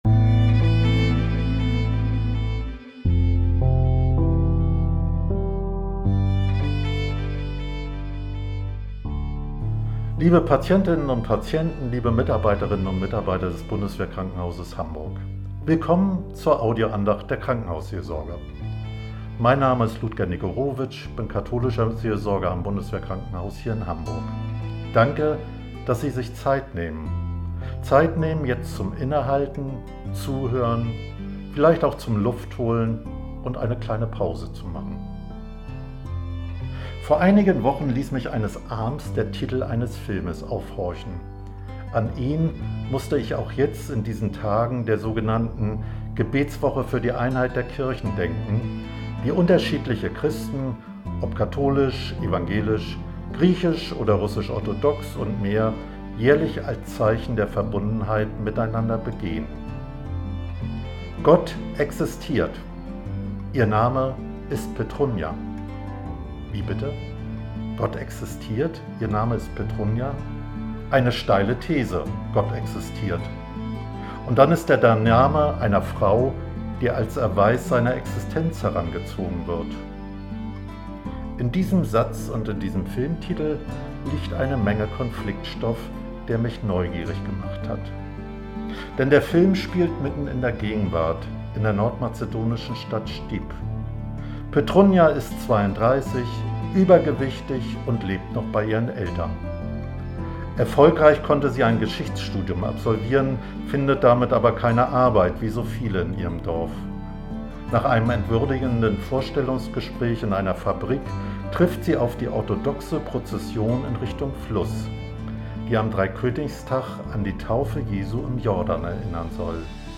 Andachten